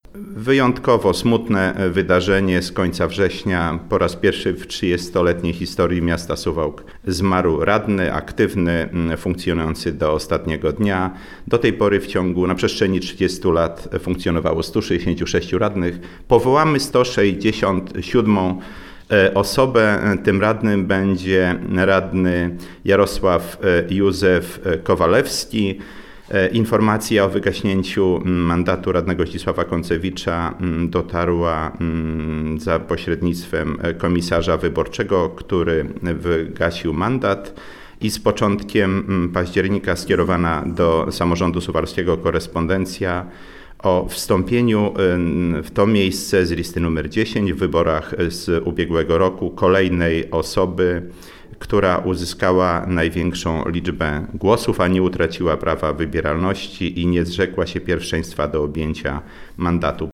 O szczegółach mówi Zdzisław Przełomiec, przewodniczący Rady Miejskiej w Suwałkach.